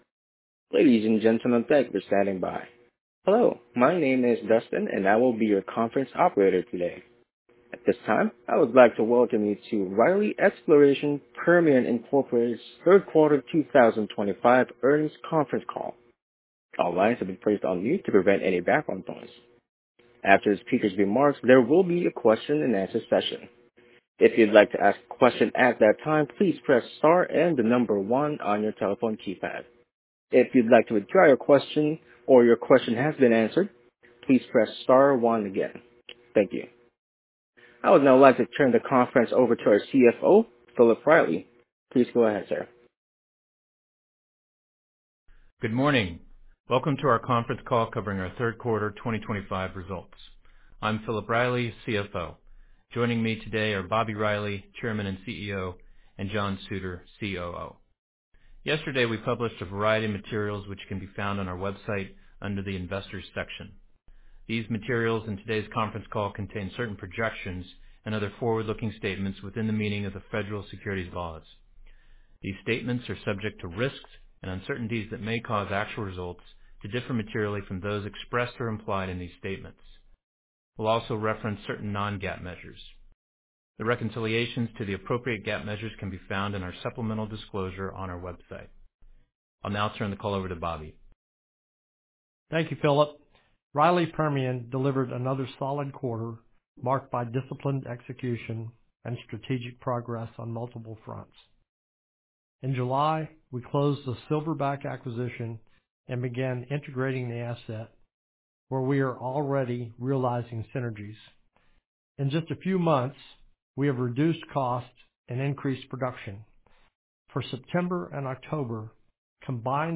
Earnings Call Recording (opens in new window)